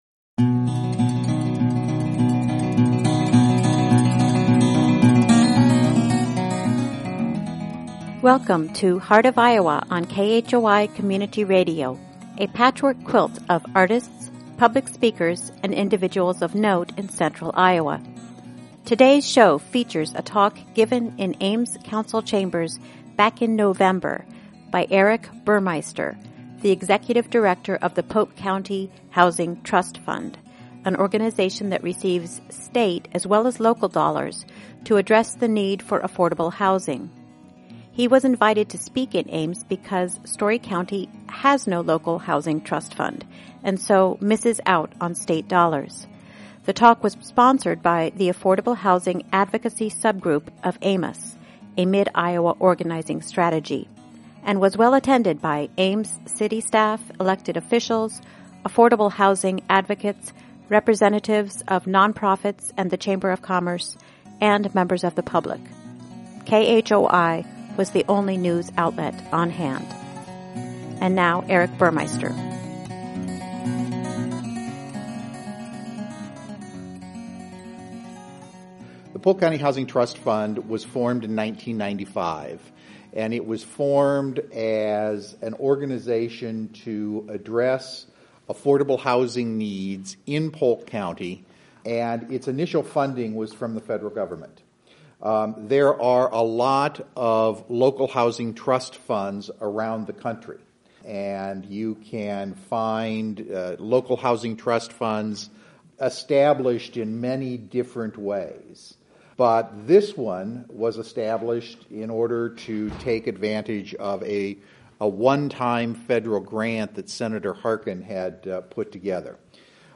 The Affordable Housing advocacy group of AMOS, a Mid-Iowa Organizing Strategy, hosted the talk in Ames City Council Chambers on November 10. Story County is one of the few counties in Iowa that does not have such a trust fund, and is therefore ineligible for the state money for affordable housing that is distributed through these mechanisms.